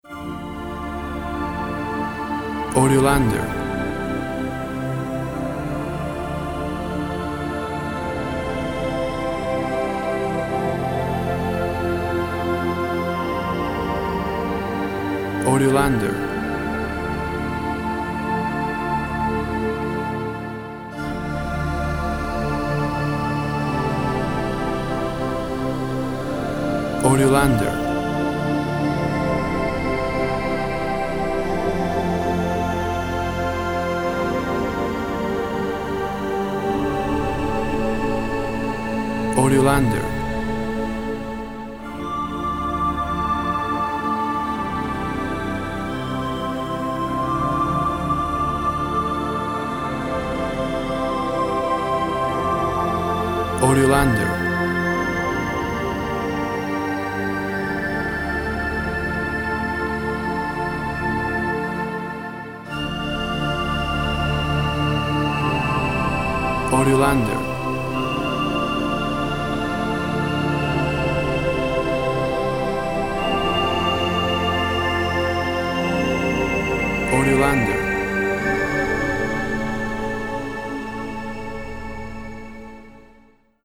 Thick synth sounds with synth flute overlay.
Tempo (BPM) 45